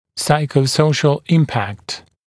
[ˌsaɪkə(u)’səuʃl ‘ɪmpækt][ˌсайко(у)’соушл ‘импэкт]психосоциальное влияние